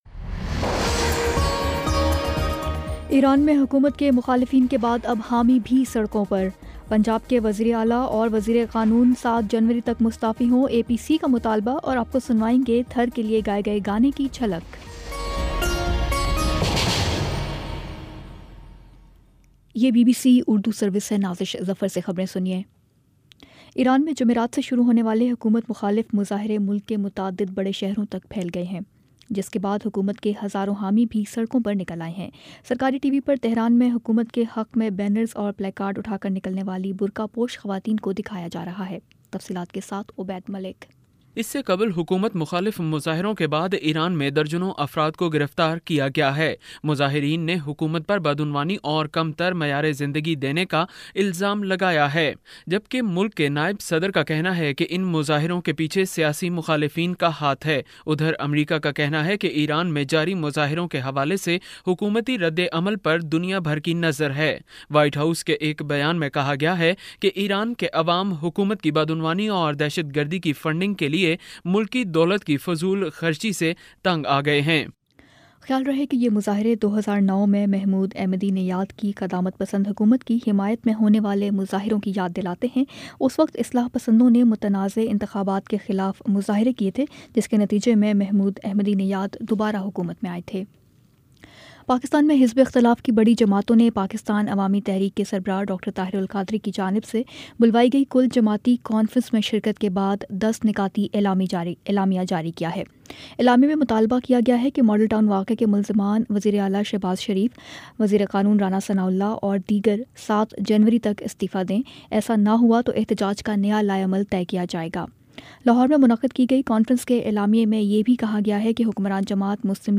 دسمبر 30 : شام چھ بجے کا نیوز بُلیٹن